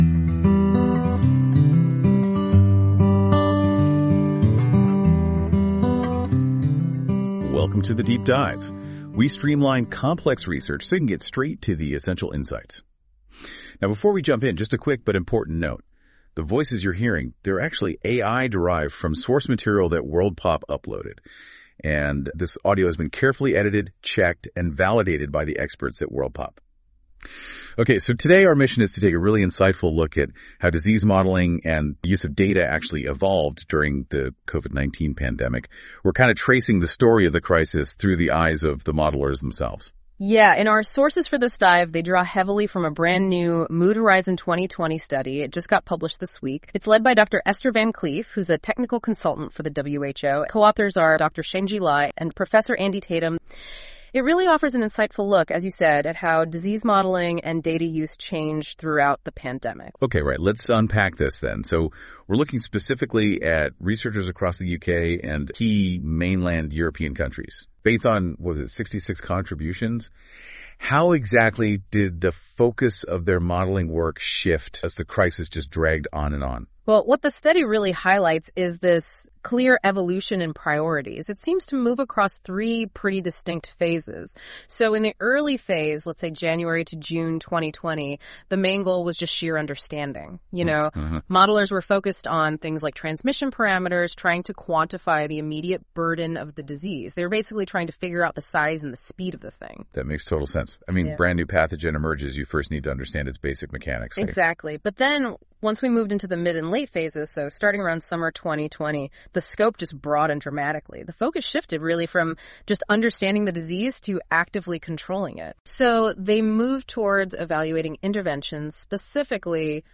This feature uses AI to create a podcast-like audio conversation between two AI-derived hosts that summarise key points of documents - in this case the "Modelling practices, data provisioning, sharing and dissemination needs for pandemic decision-making" journal article listed below.
Music: My Guitar, Lowtone Music, Free Music Archive (CC BY-NC-ND)